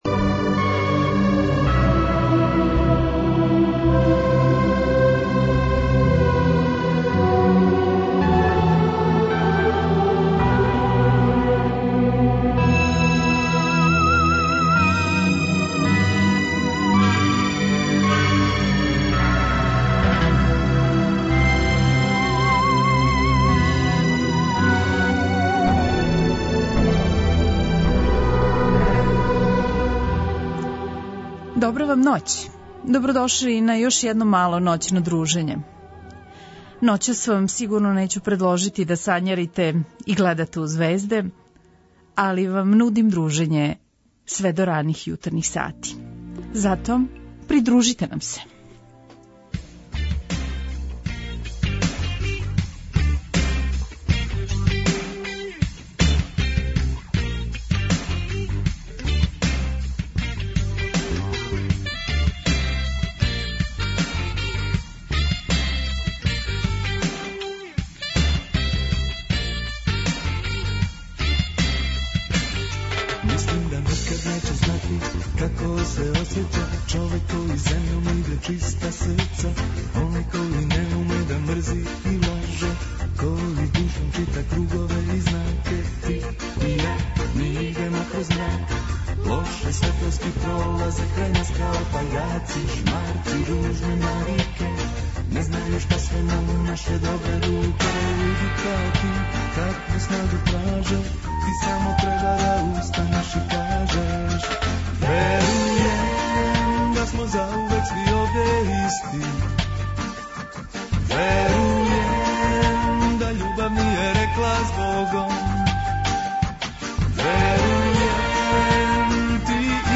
преузми : 57.31 MB Широм затворених очију Autor: Београд 202 Ноћни програм Београда 202 [ детаљније ] Све епизоде серијала Београд 202 Устанак Блузологија Свака песма носи своју причу Летње кулирање Осамдесете заувек!